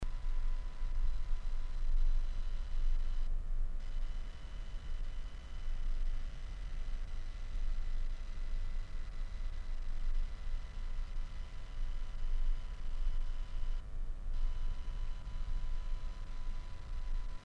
Шум/фон при записи на микрофон
Уровень записи 27dB на карте. После записи громкость увеличена на 24 dB, для лучшей слышимости.